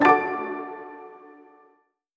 button-edit-select.wav